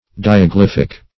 Diaglyphic \Di`a*glyph"ic\